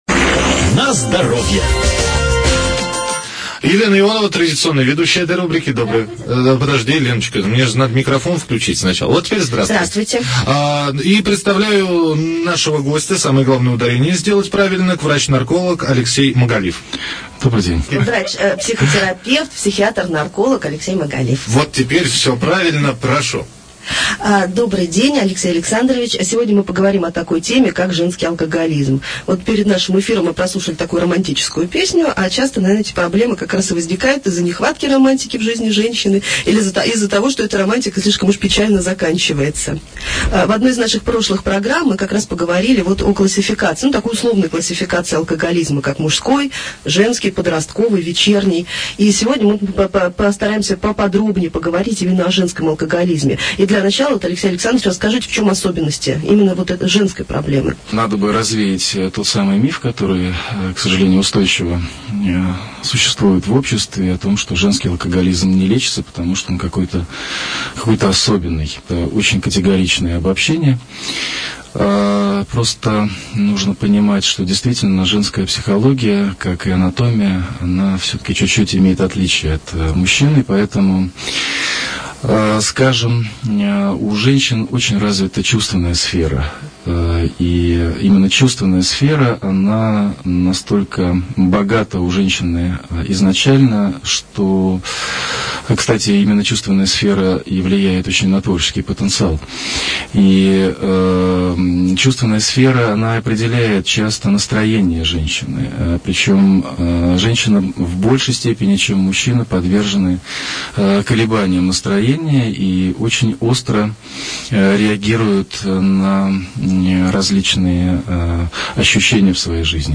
Аудио интервью по алкоголизму